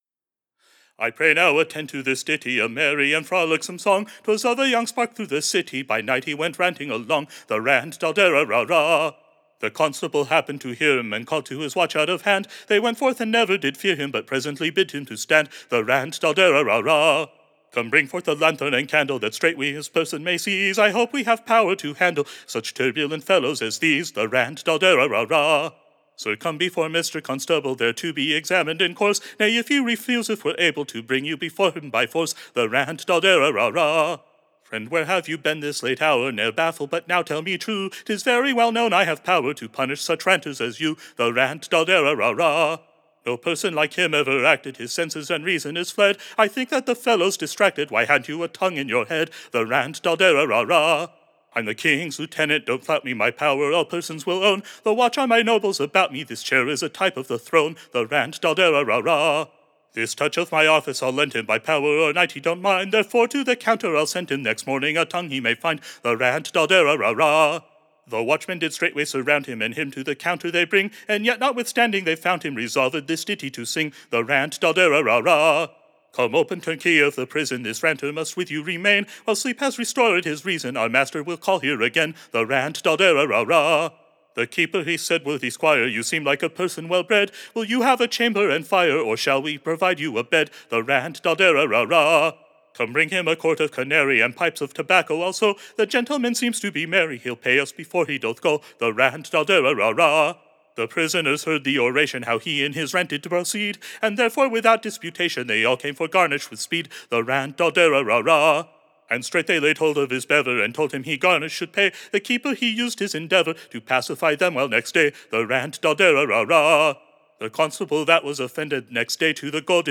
Ballad
Tune Imprint To a pleasant new Tune, called, The Rant.